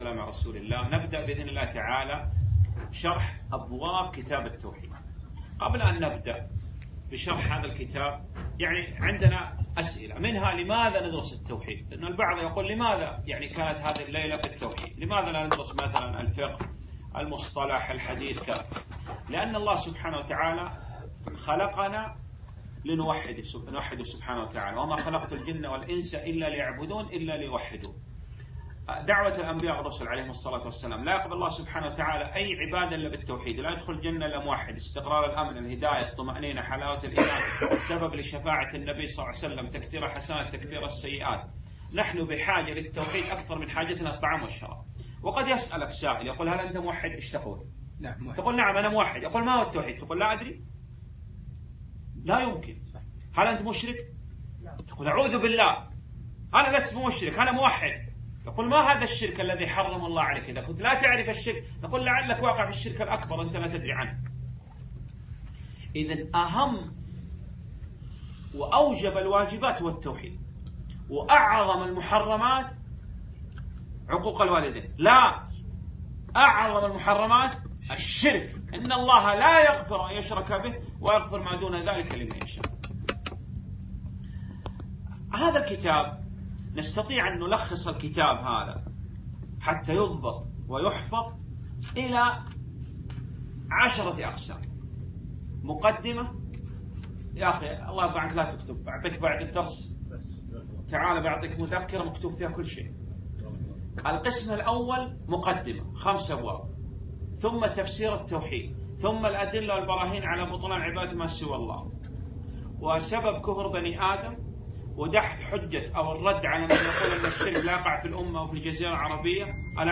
شرح من أبواب كتاب التوحيد في ديوانية شباب صباح الأحمد 1440هــ